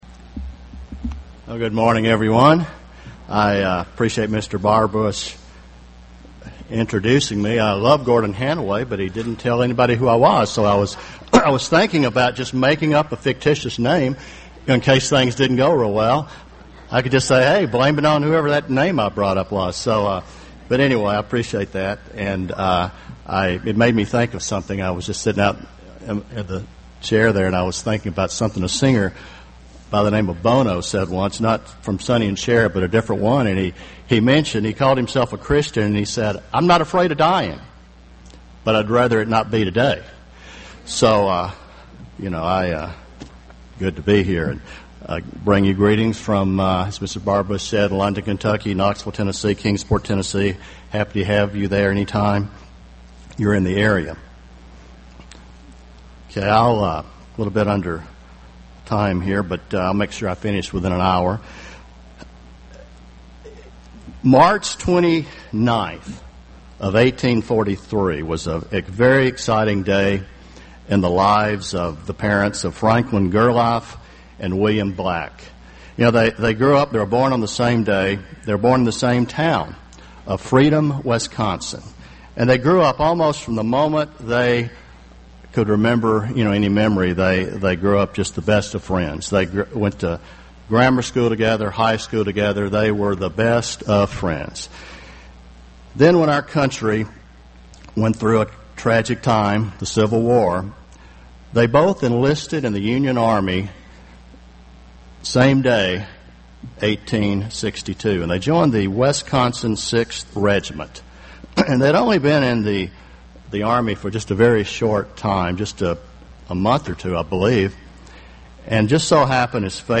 This sermon was given at the Jekyll Island, Georgia 2011 Feast site.